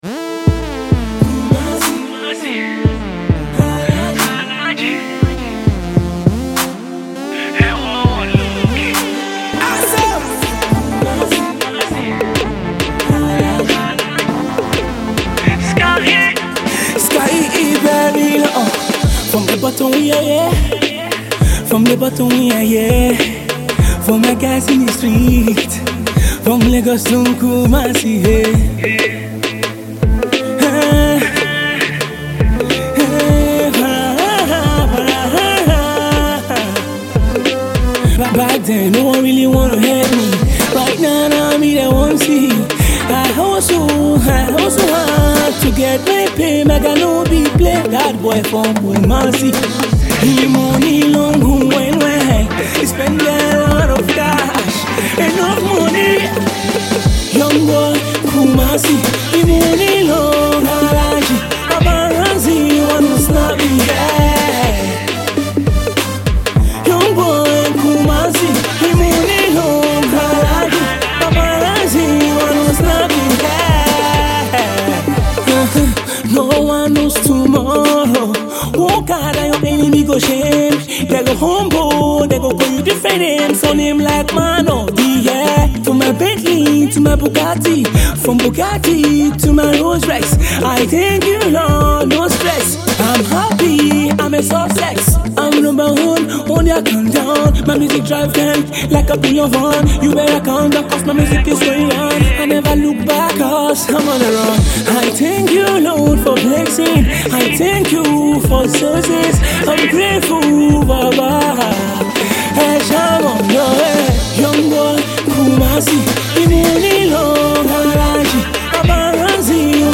AudioPop